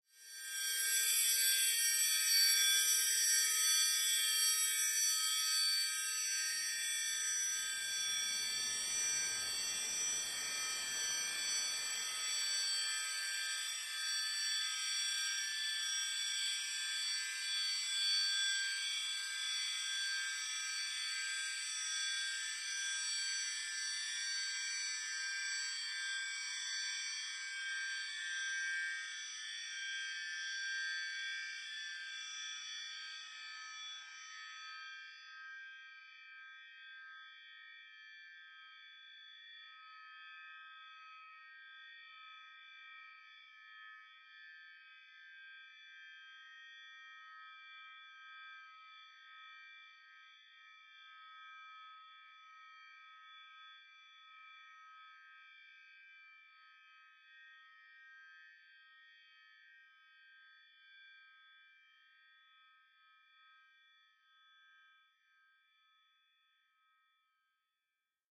Ambiance atmosphere scanner fantasy